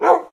wolf_bark2.ogg